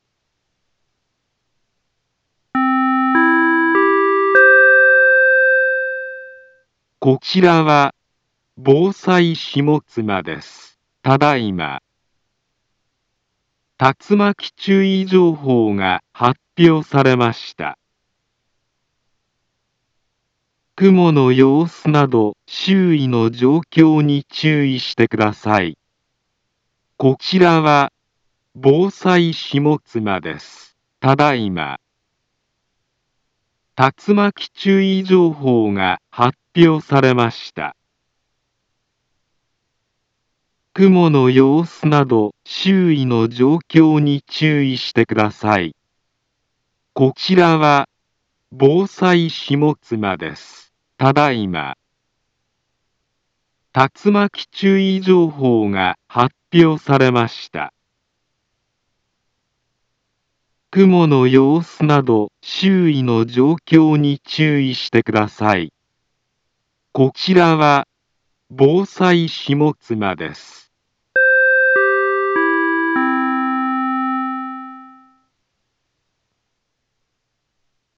Back Home Ｊアラート情報 音声放送 再生 災害情報 カテゴリ：J-ALERT 登録日時：2023-09-08 15:05:06 インフォメーション：茨城県南部は、竜巻などの激しい突風が発生しやすい気象状況になっています。